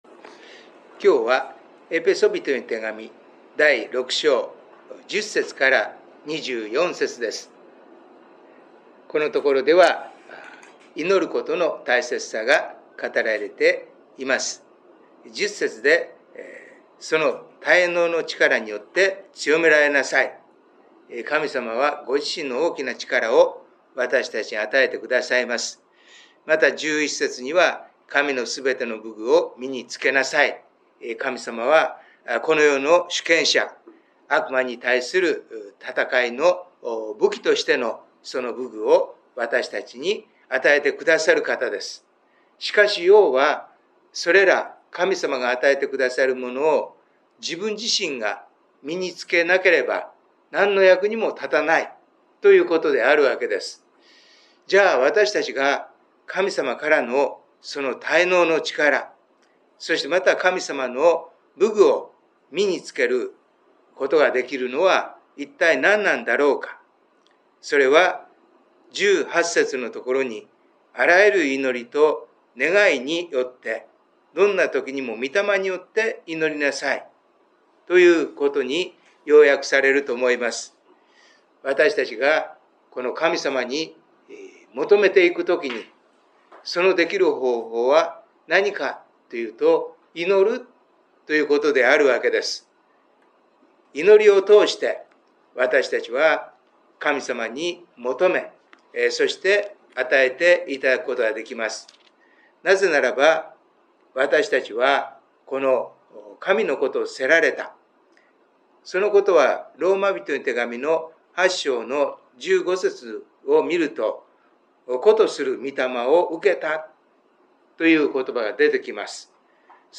礼拝メッセージ
オーディオ礼拝メッセージです。